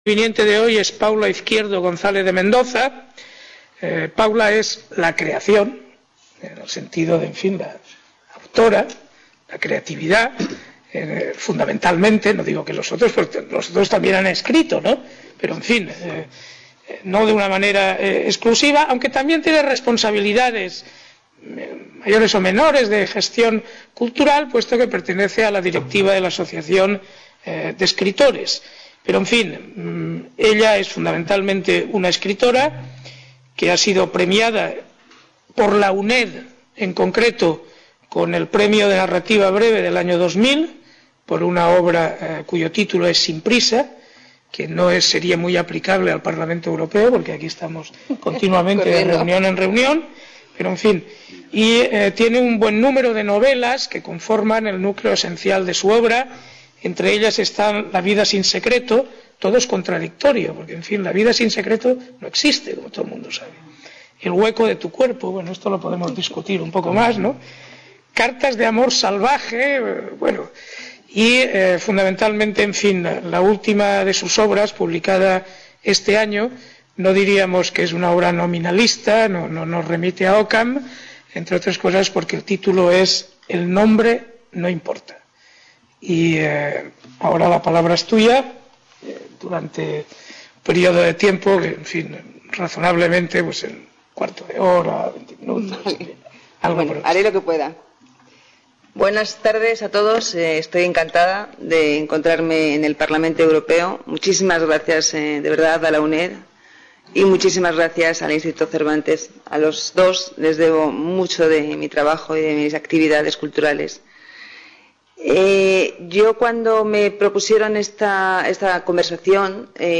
Mesa redonda: Literatura española y Europa | Repositorio Digital
Reunion, debate, coloquio...